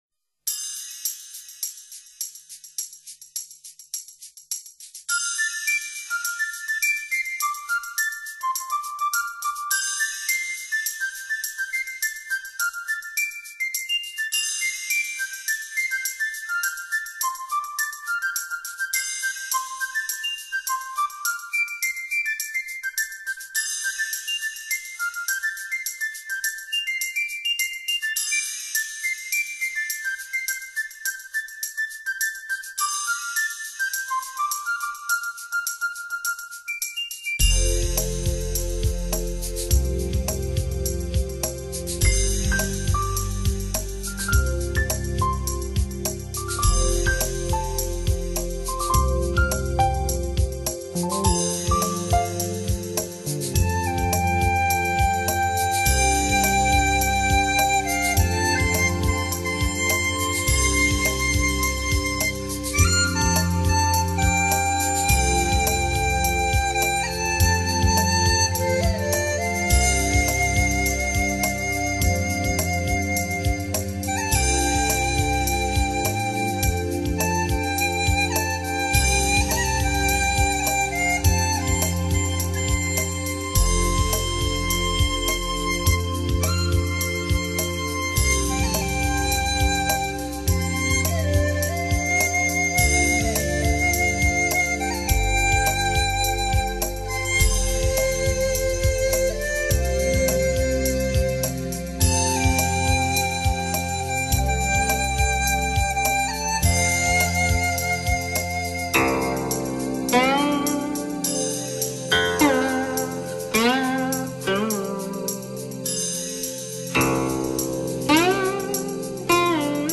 发烧音乐，值得收藏！